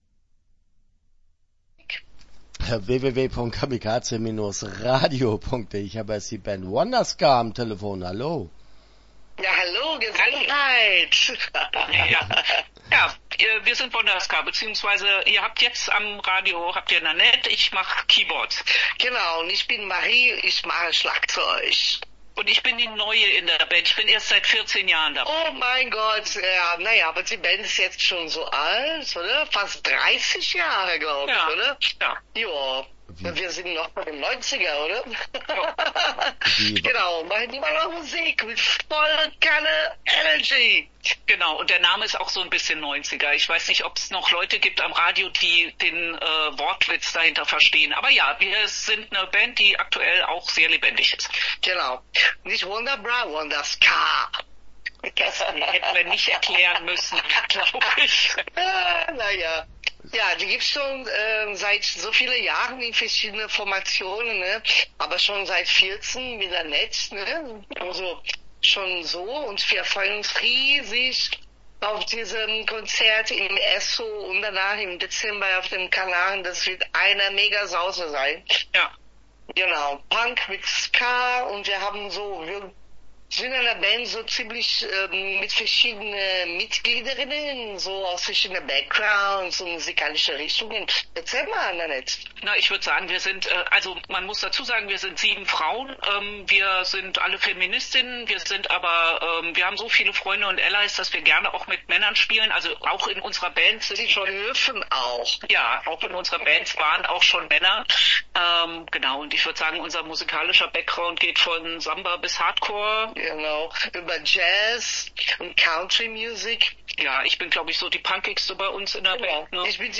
Start » Interviews » wonderSka